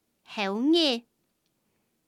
Hong/Hakka_tts